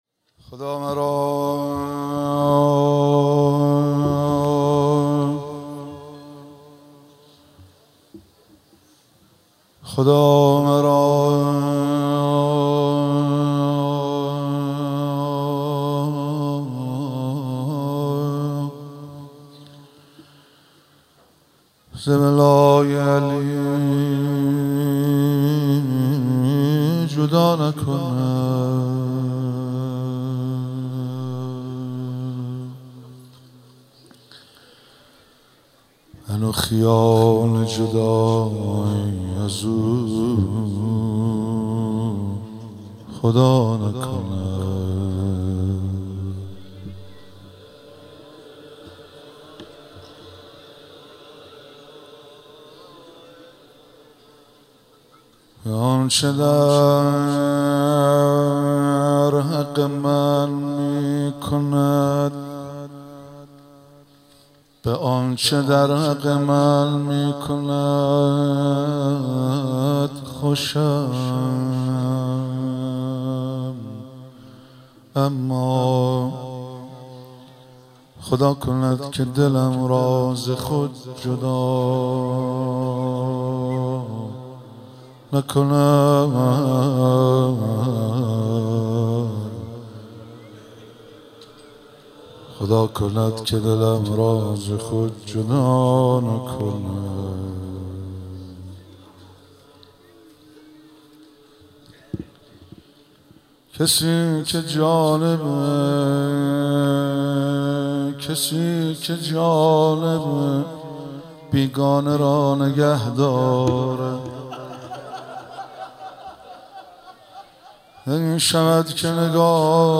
مراسم مناجات خوانی و احیای شب نوزدهم ماه رمضان 1444